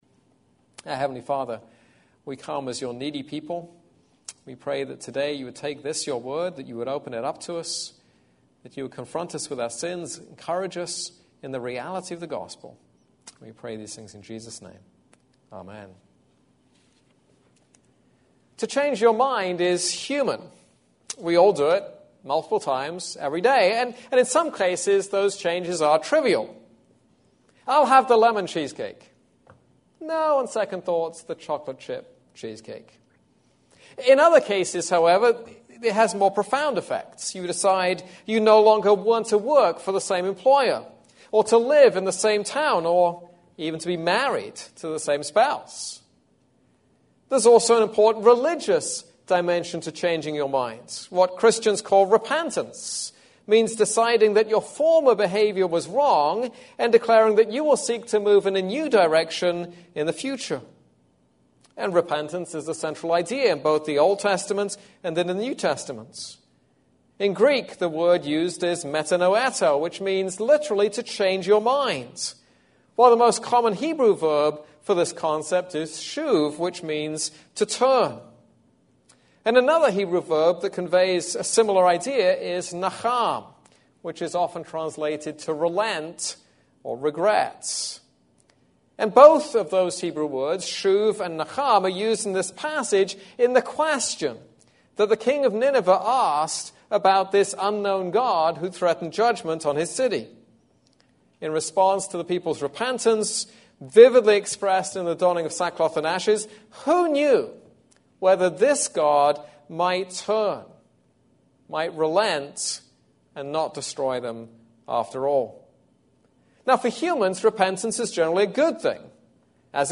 This is a sermon on Jonah 3:6-4:2.